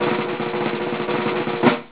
drums.wav